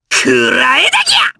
Lakrak-Vox_Attack4_jp.wav